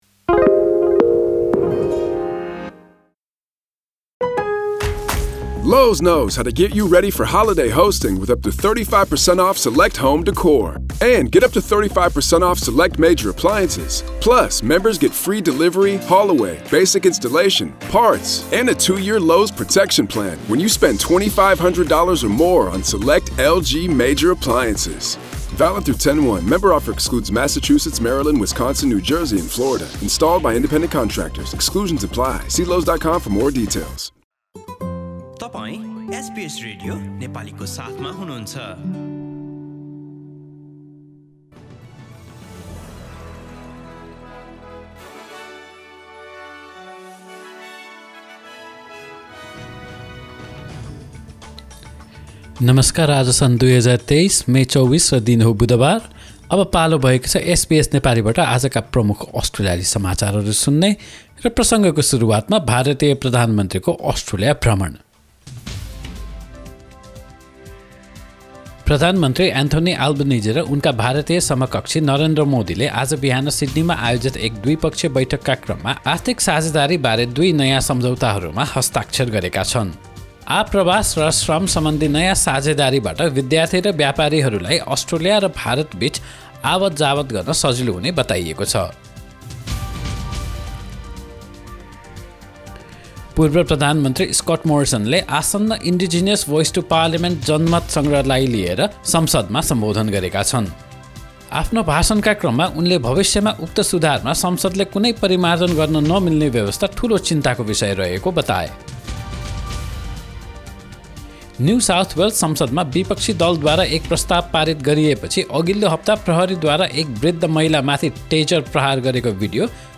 एसबीएस नेपाली प्रमुख अस्ट्रेलियाली समाचार: बुधवार, २४ मे २०२३